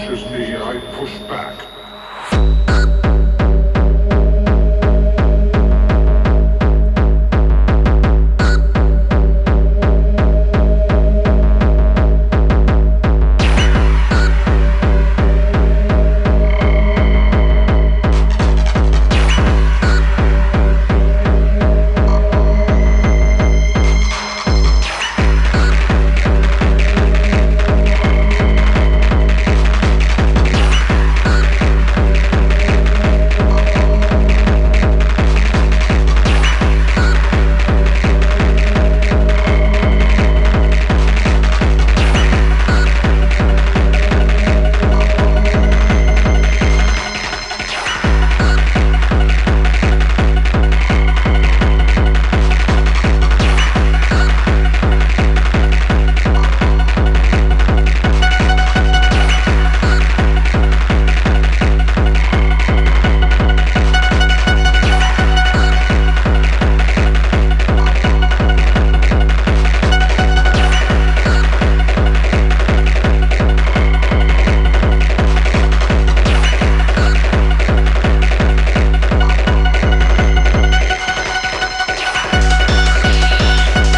dos contundentes tracks originales